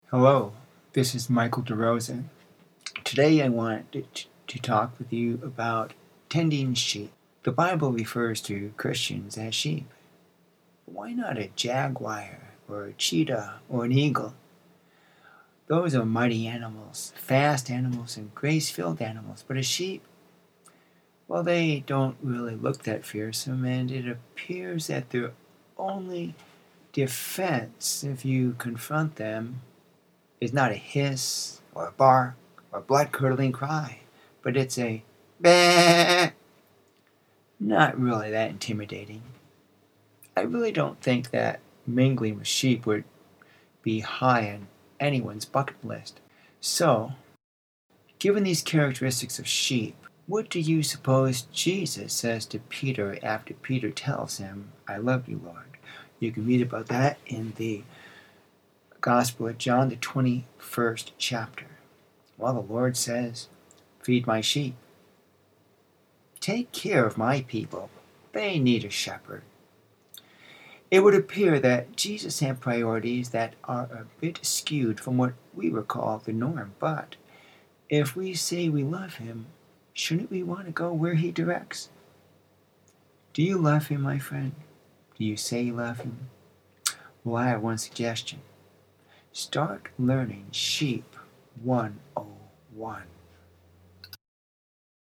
Sermonette-Do-you-love-Him.mp3